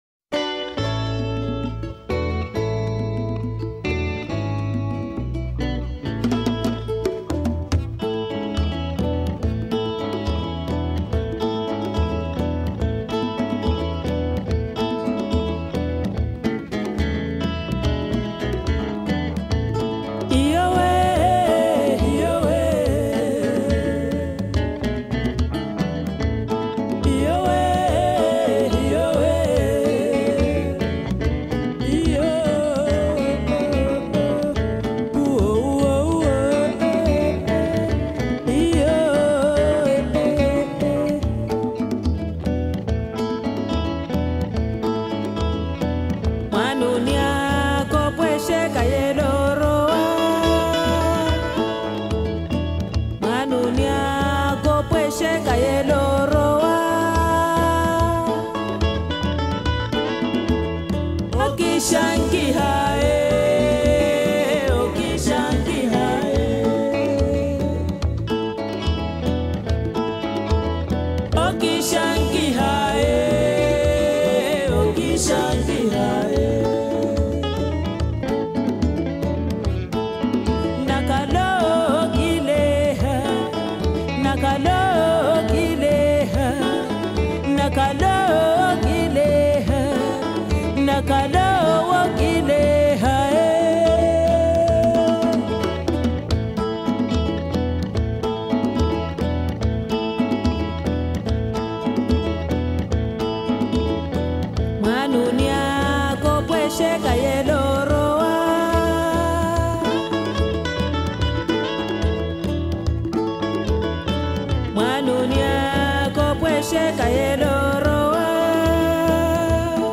Genero: Traditional Folk